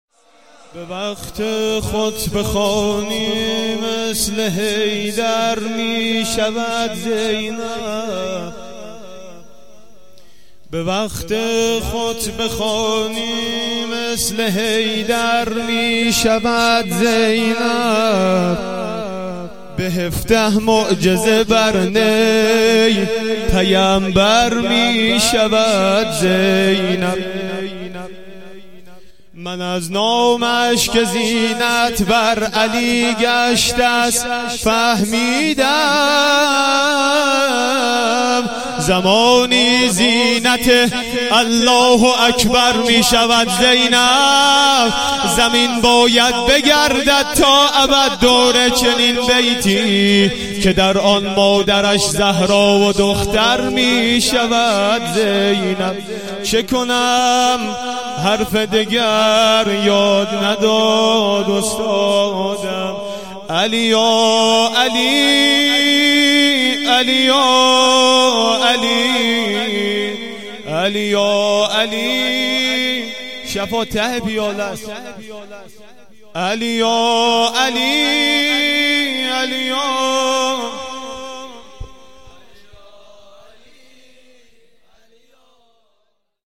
مراسم فاطمیه دوم 96.11.27